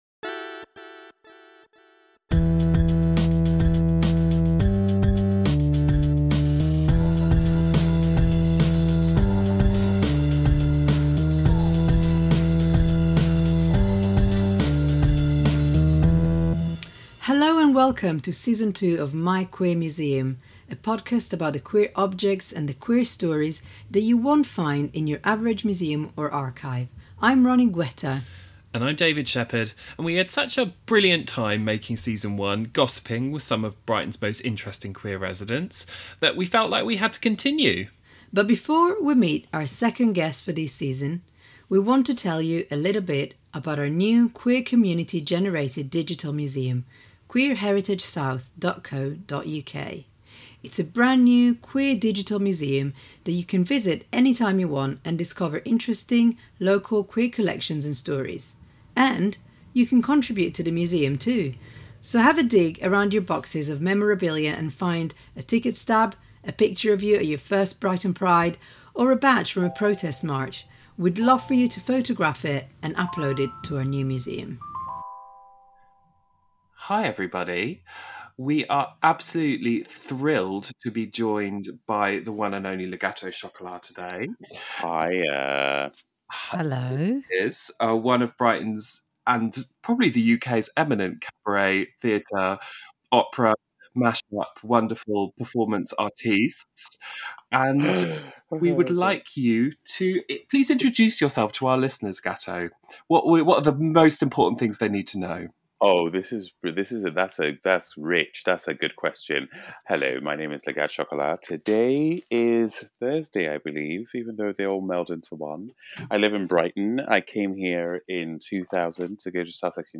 In this interview Le Gateau talks about his first time on stage, his operaTIC foremothers, and about his extravagant collection of plants... Recorded remotely on May 10, 2021